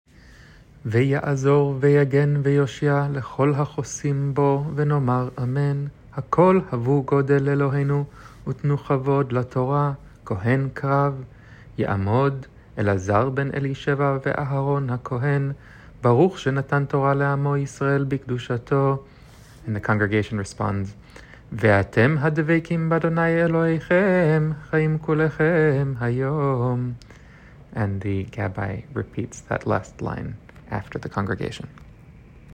Reference recordings for the parts a gabbai leads or recites during the Torah service.
Said by the gabbai before the kohen aliyah.